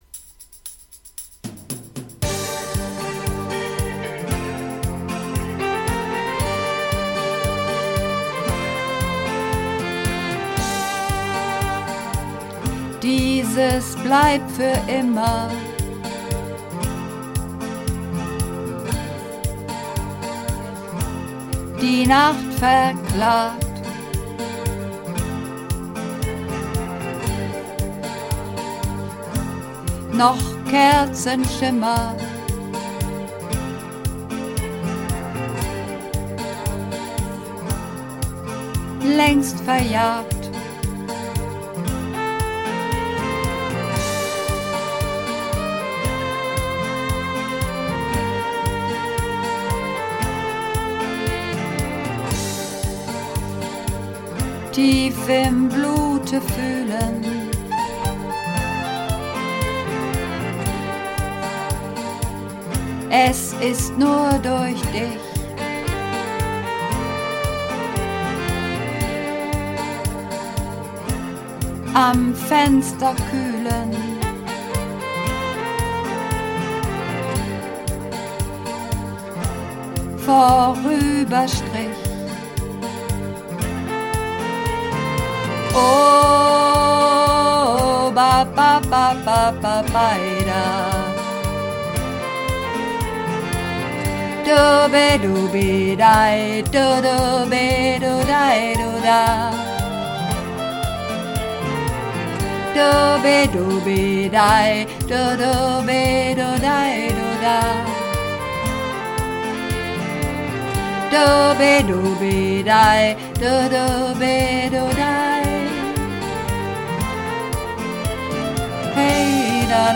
Übungsaufnahmen - Am Fenster
Am Fenster (Alt)
Am_Fenster__1_Alt.mp3